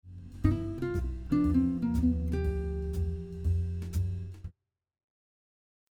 All the examples will be over a G 7 chord but they would also work over a G 9 chord.
For the first lick, we are mostly thinking of the scale notes for a G mixolydian scale, which has the notes G, A, B, C, D, E, and F. The only exception is the approach note which is a half step below the 3rd of the chord.
blues lick 1